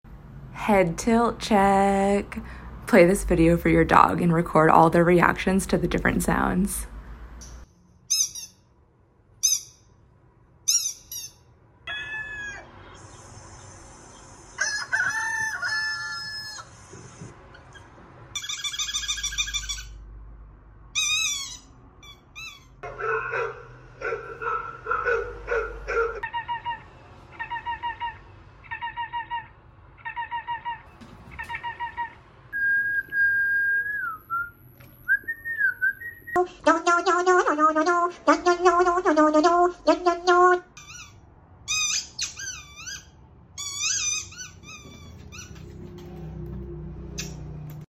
Moose loves his squeaky toys sound effects free download